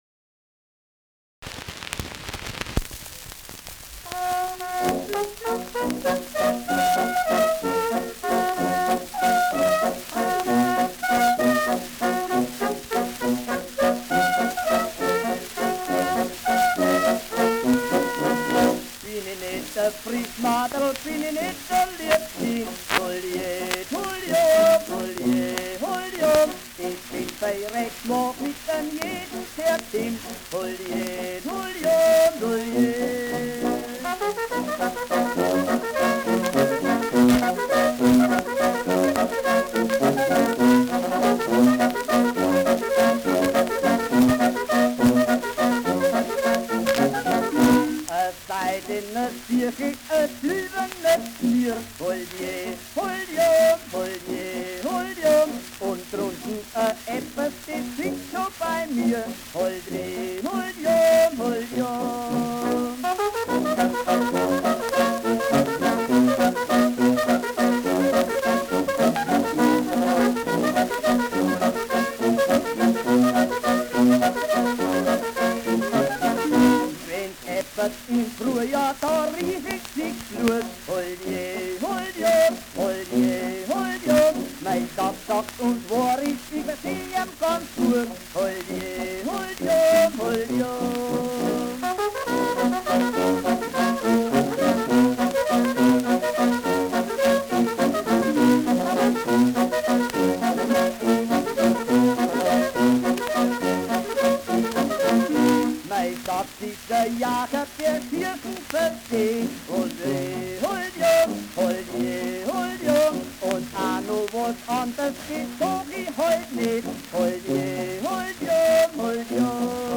Happinger Schnadahüpfl : mit Gesang [Happurger Schnaderhüpfel : mit Gesang]
Schellackplatte
Stärkeres Grundrauschen : Gelegentlich leichtes bis stärkeres Knacken